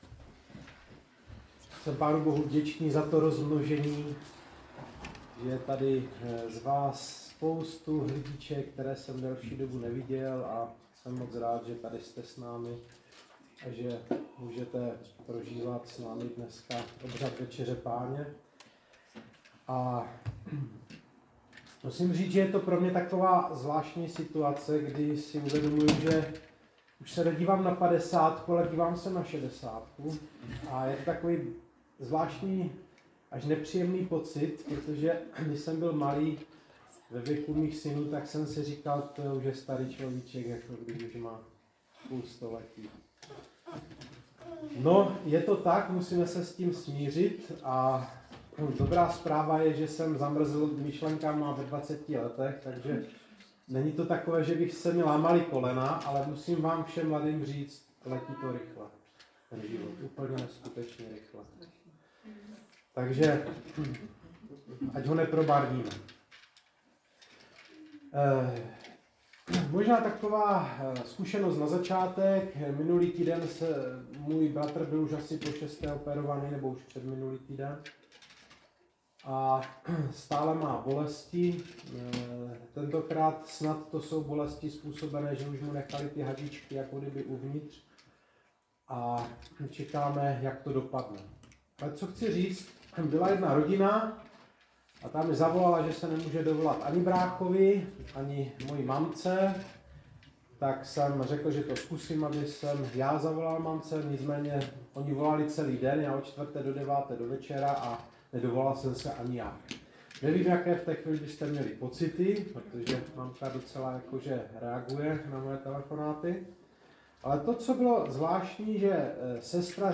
Kázání sboru CASD Vrbno pod Pradědem